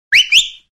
Whistle5.wav